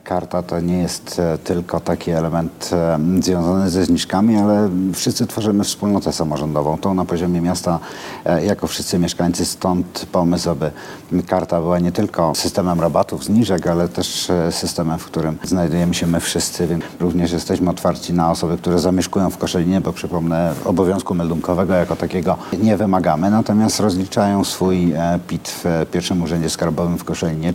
O karcie i kto może ją otrzymać mówi Piotr Jedliński, prezydent Koszalina.